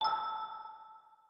menu-freeplay-click.wav